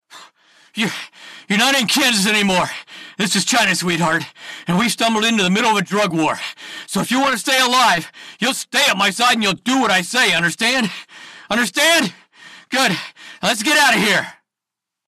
VIDEO GAME ACTION
VIDEO GAME Action Character.mp3